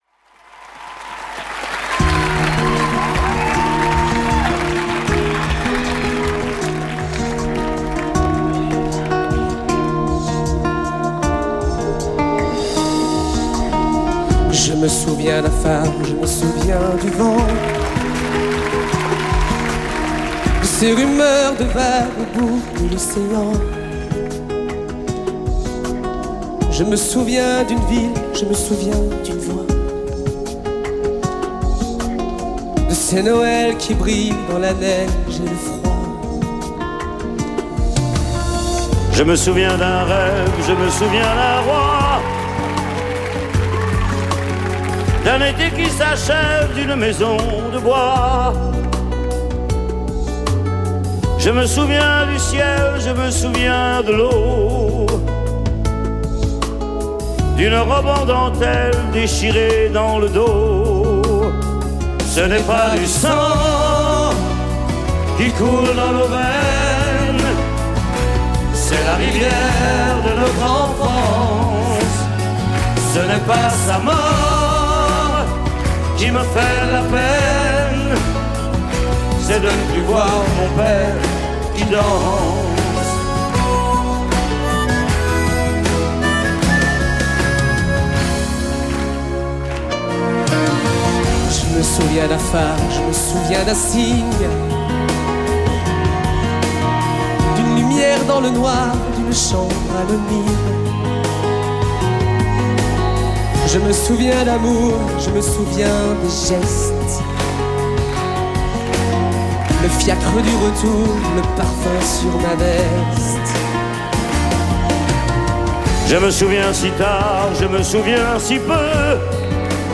Источник трека  - запись с концерта.